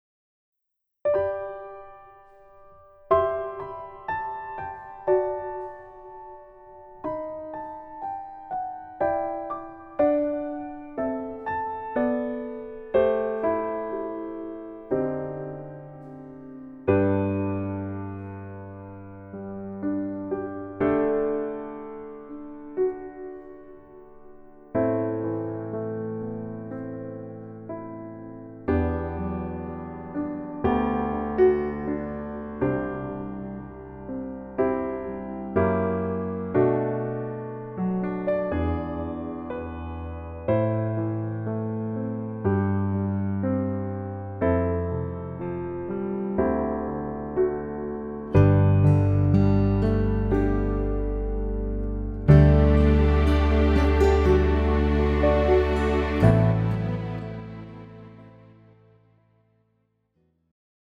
장르 가요 구분 Pro MR